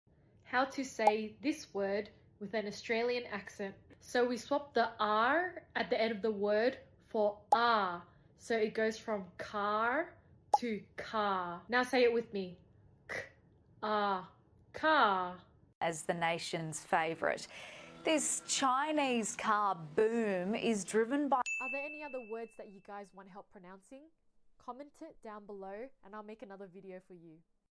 How did you go pronouncing “car” in an Aussie accent? (Just to note that accents vary and not all aussie speak the same).